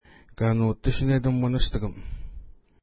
Pronunciation: ka:nu:ti:ʃənets uministukəm
Pronunciation